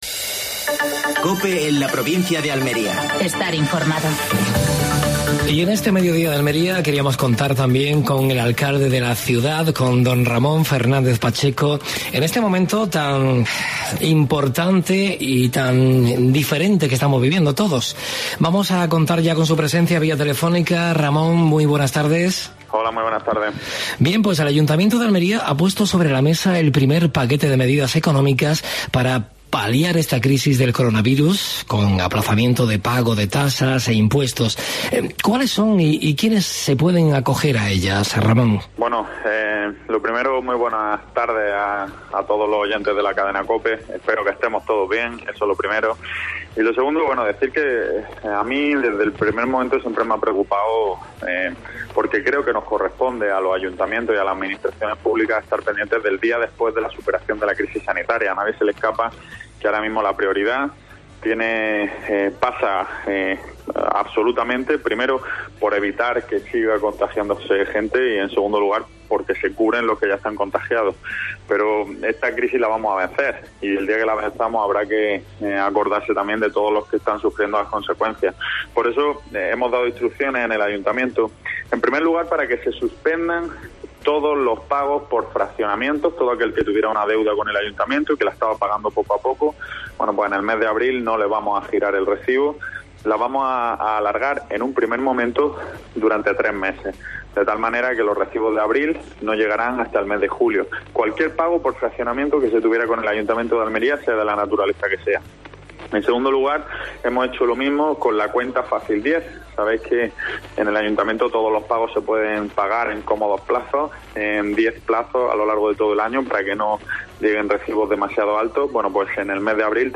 Actualidad en Almería. Entrevista a Ramón Fernández-Pacheco (alcalde de Almería).